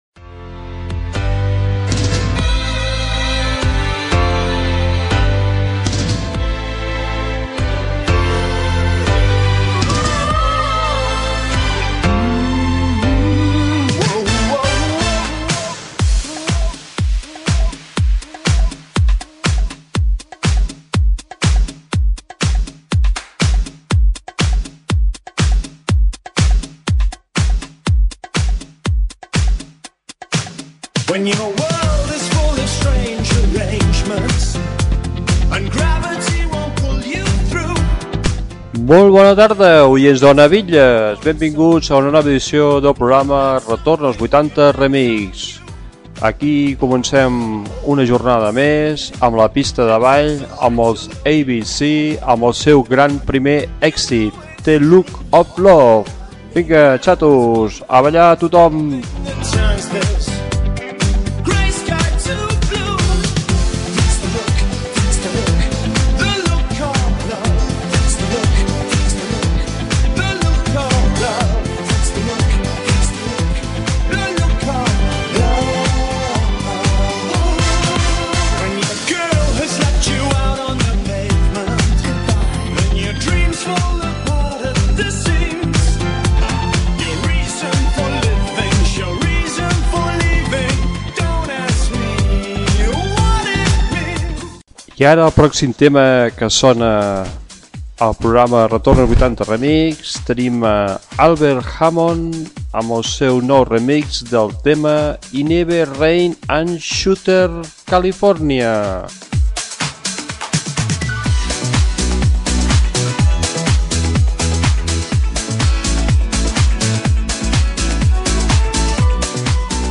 Sintonia, presentació de dos temes musicals Gènere radiofònic Musical